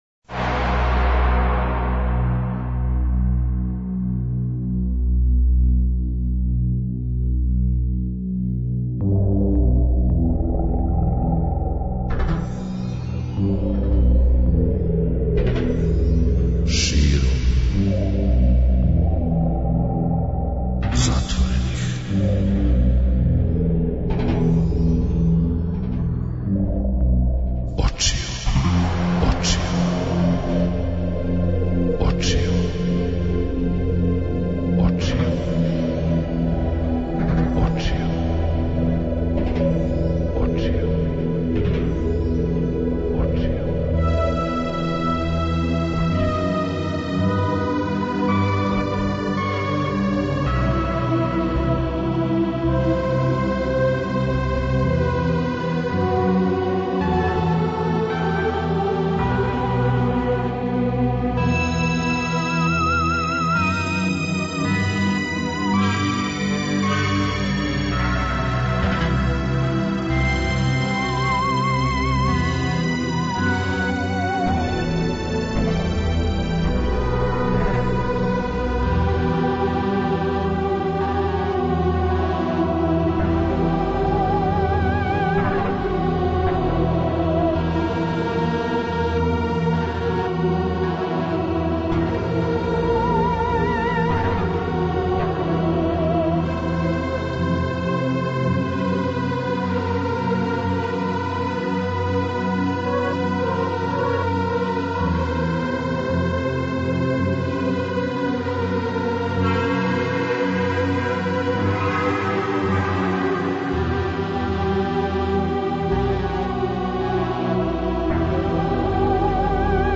Слушаоци ће имати прилике да преслушају премијерно његов нови албум на коме се налази десет ауторских инструменталних композиција, које је компоновао, аранжирао, програмирао и одсвирао.
преузми : 57.27 MB Широм затворених очију Autor: Београд 202 Ноћни програм Београда 202 [ детаљније ] Све епизоде серијала Београд 202 Говор и музика Састанак наше радијске заједнице We care about disco!!!